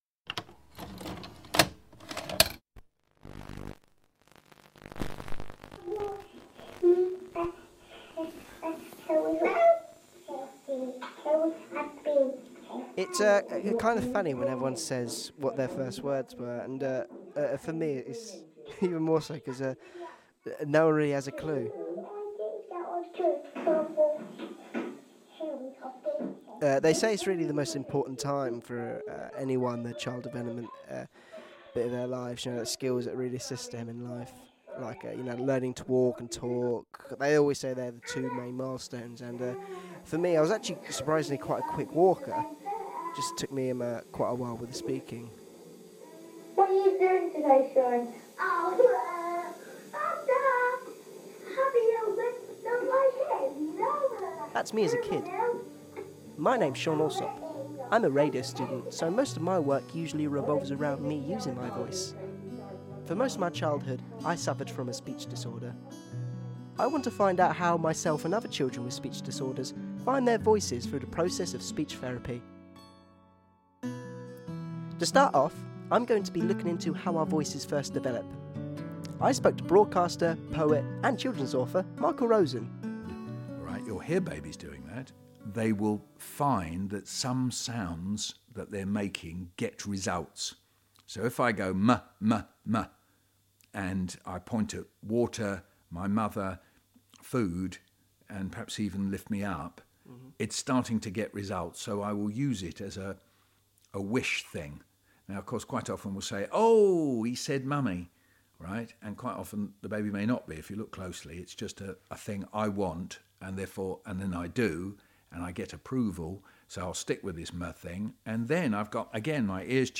Radio Documentary featuring contributions from Children's author and poet Michael Rosen and Meath specialist Speech & Language primary school.